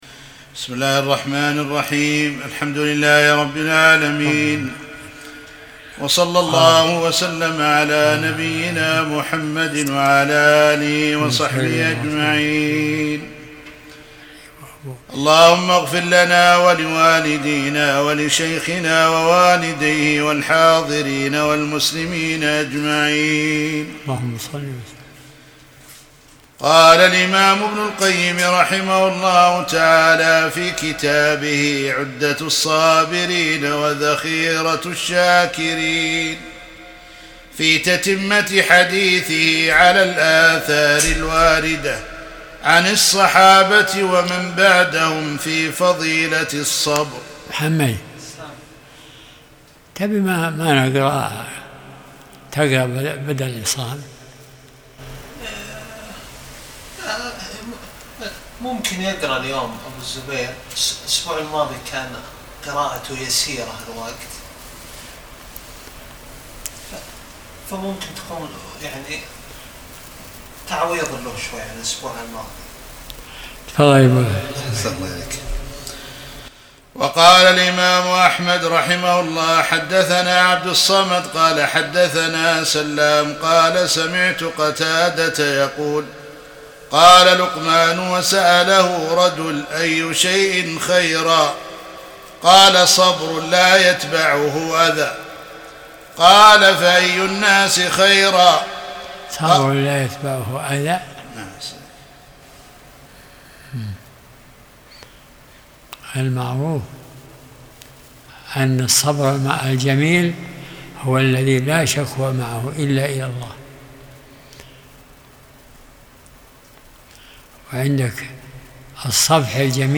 درس الأربعاء 37